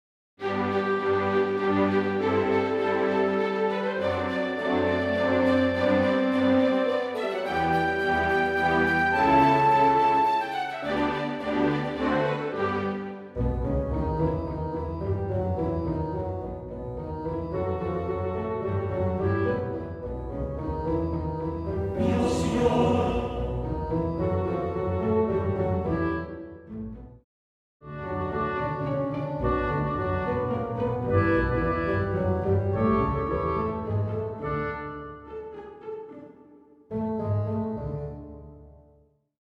Full orchestral accompaniment/karaoke music tracks
Full Orch accompaniment